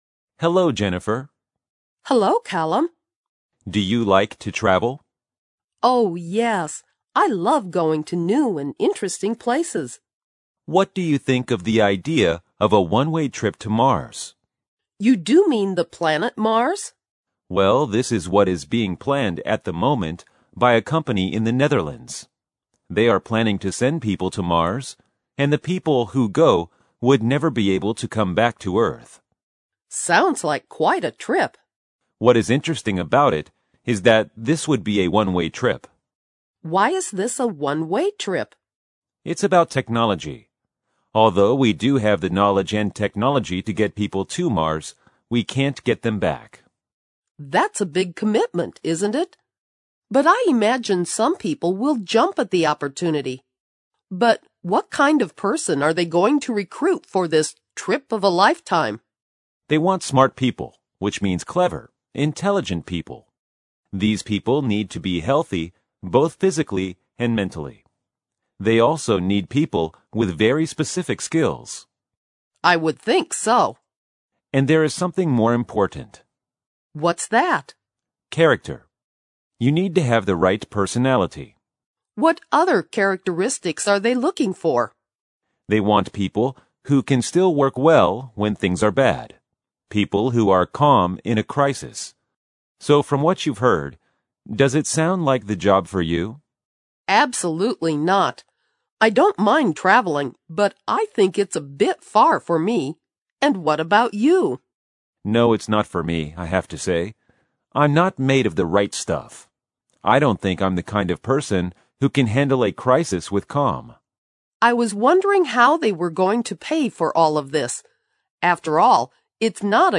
2015年英语专业四级听力真题 对话1